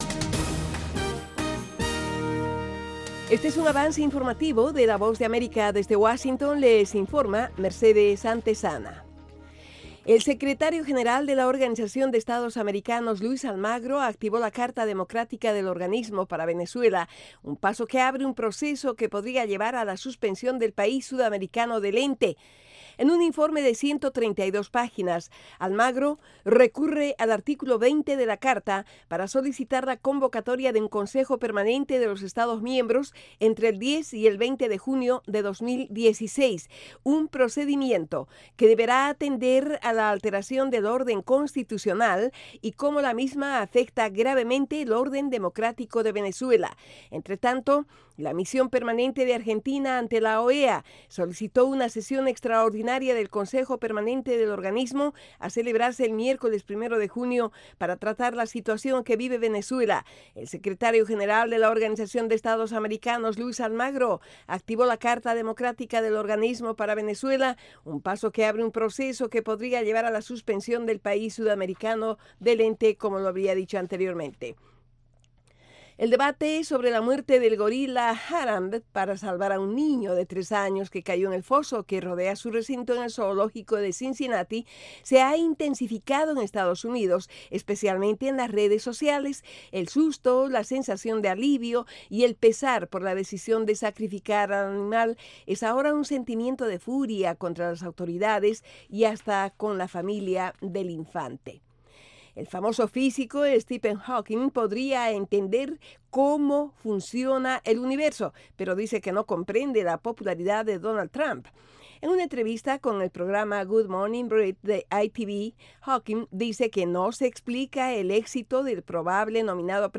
Avance Informativo - 10:00am